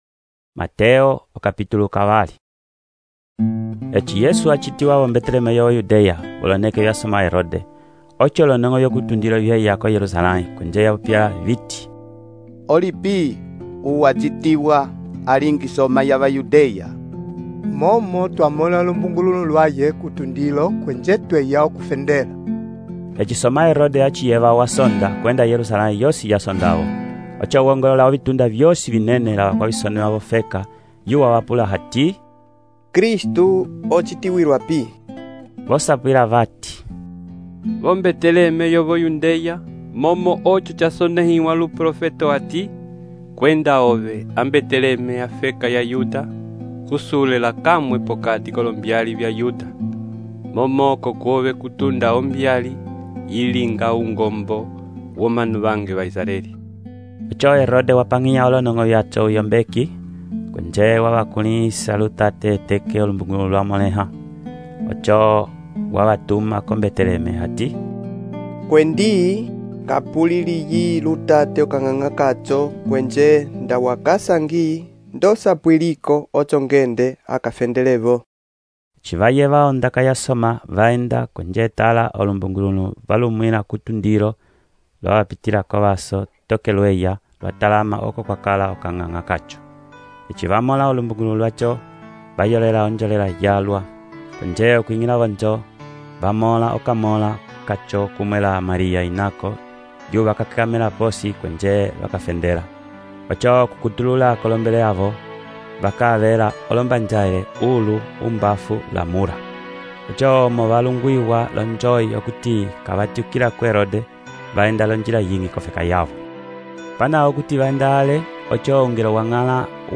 texto e narração , Mateus, capítulo 2